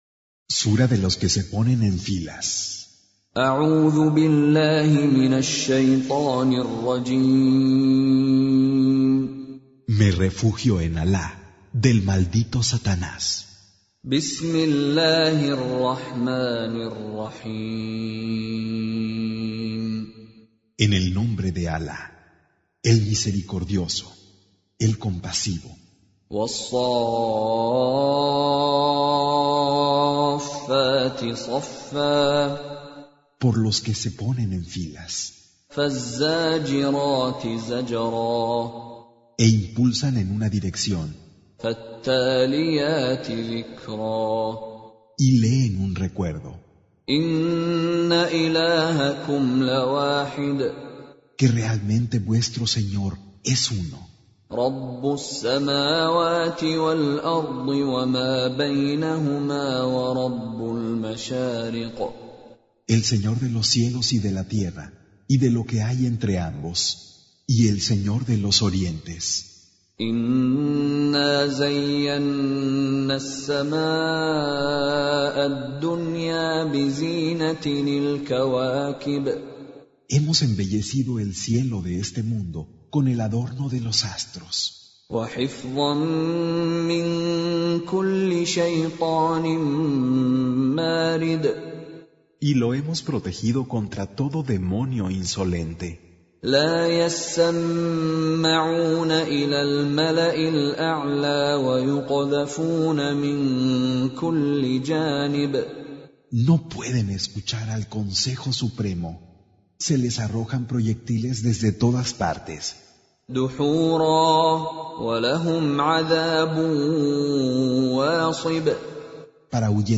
Con Reciter Mishary Alafasi
Surah Sequence تتابع السورة Download Surah حمّل السورة Reciting Mutarjamah Translation Audio for 37. Surah As-S�ff�t سورة الصافات N.B *Surah Includes Al-Basmalah Reciters Sequents تتابع التلاوات Reciters Repeats تكرار التلاوات